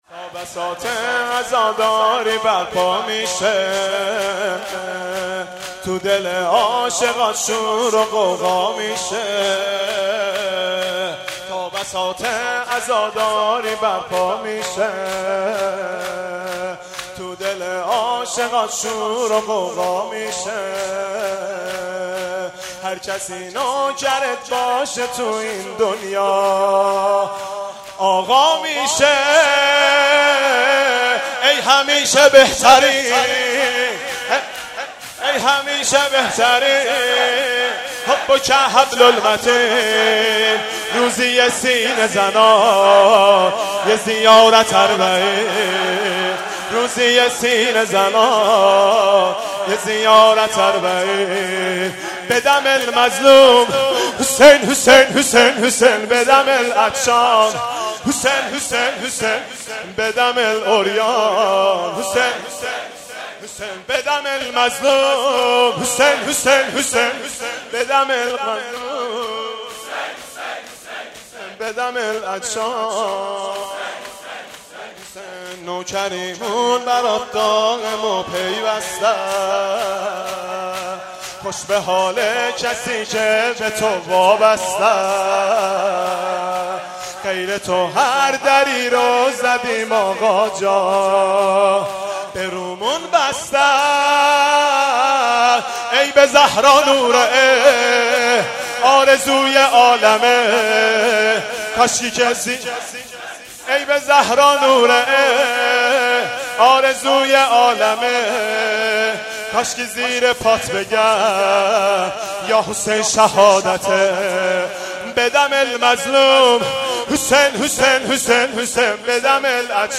مداحی اربعین عربی